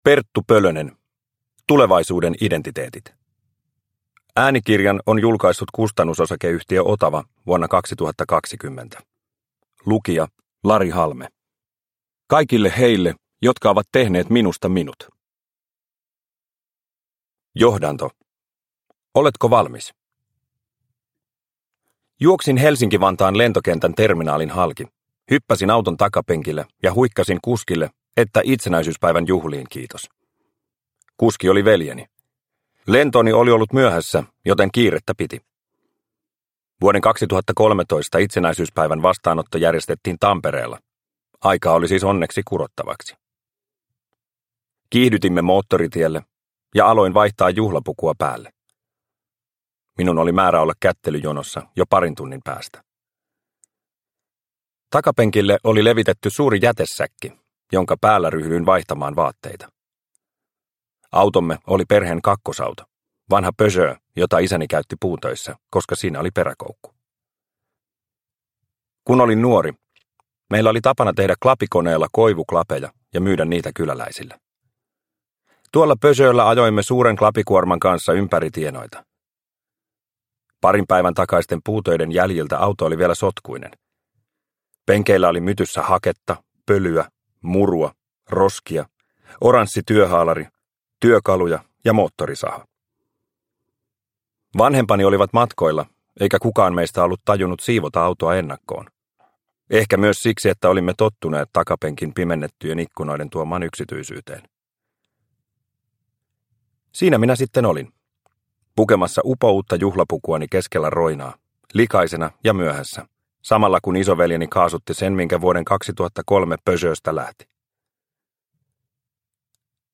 Tulevaisuuden identiteetit – Ljudbok – Laddas ner